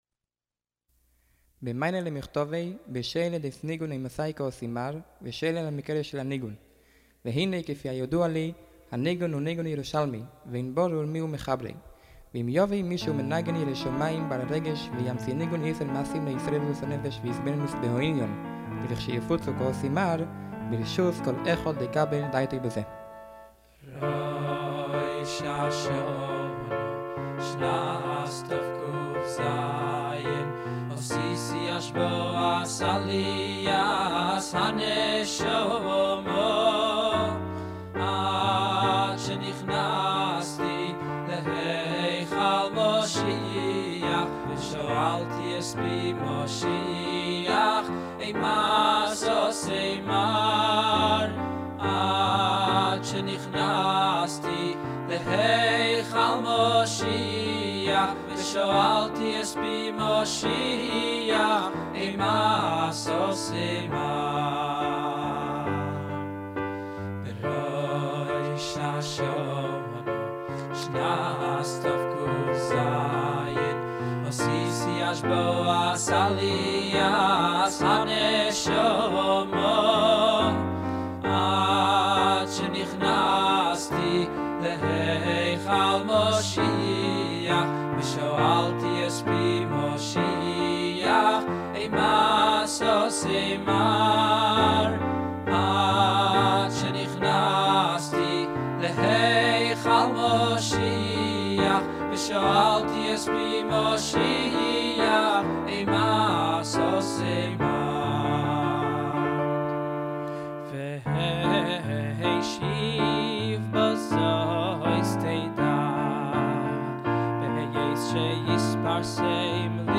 הולחן ניגון חדש ל"יפוצו מעיינותיך חוצה" ● להאזנה